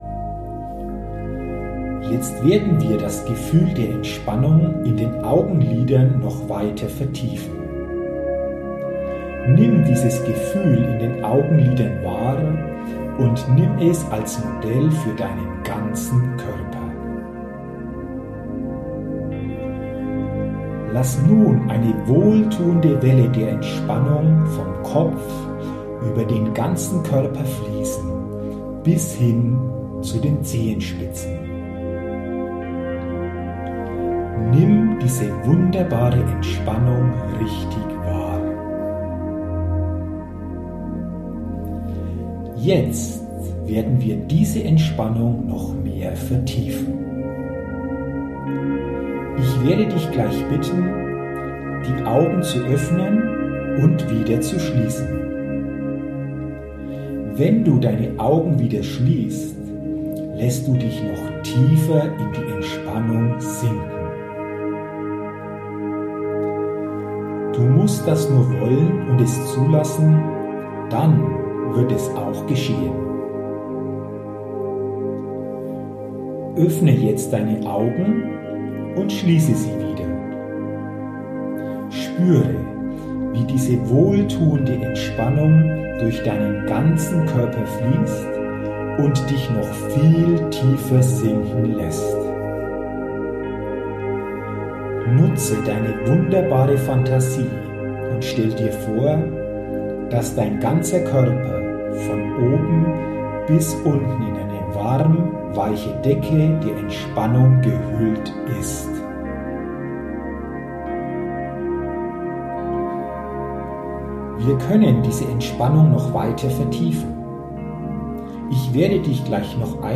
Geführte Hypnose-Meditation